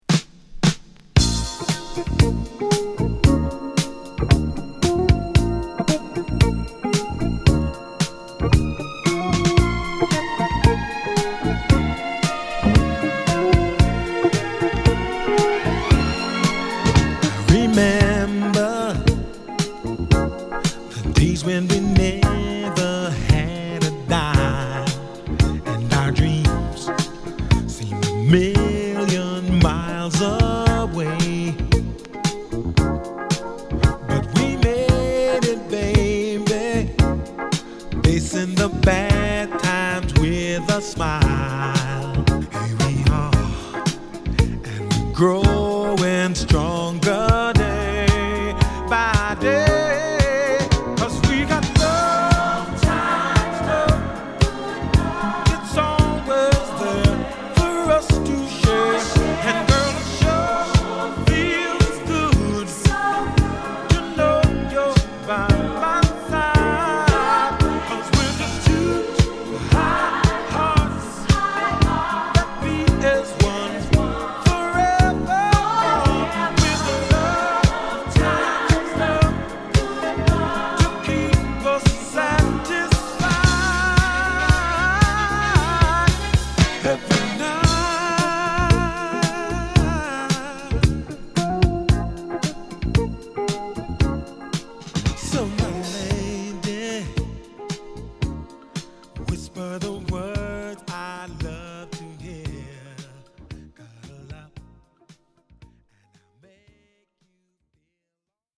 この時代らしいアーバン・ディスコ！
(Stereo)